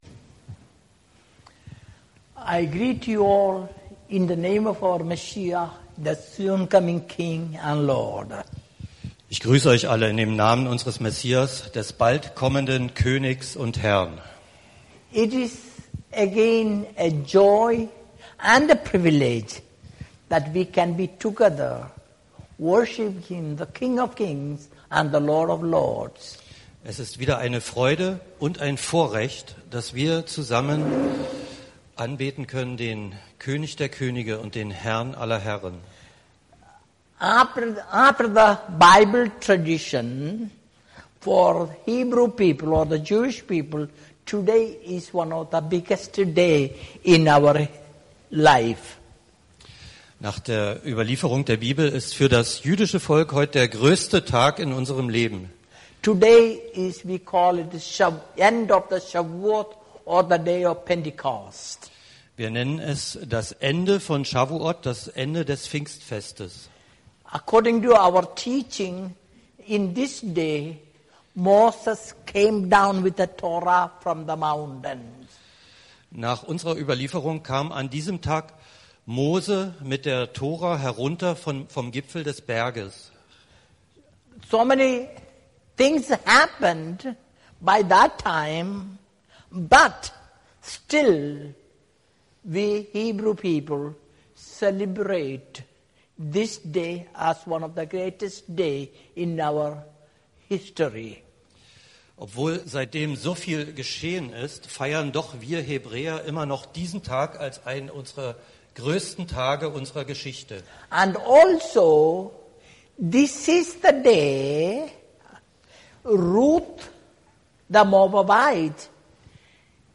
Hier folgt eine Predigt zu diesem Thema vom 12. Juni 2016, ca. 43 Minuten.